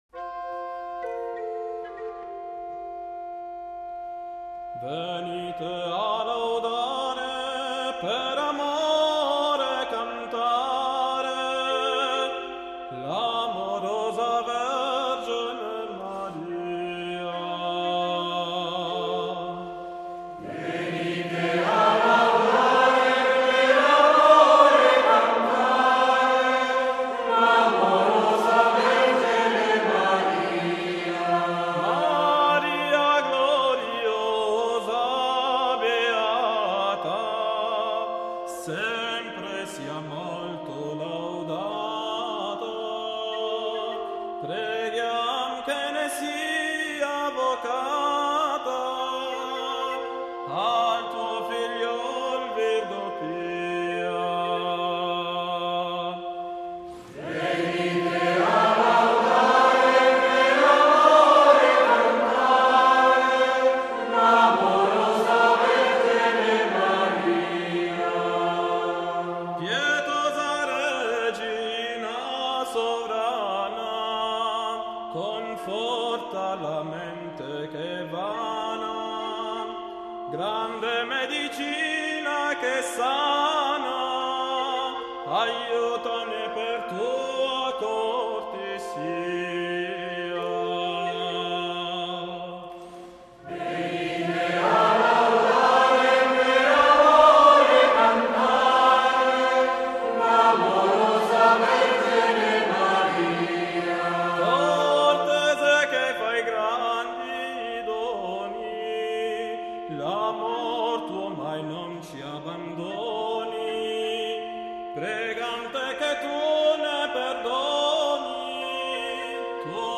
Venite a laudare (esecuzione ensemble Micrologus)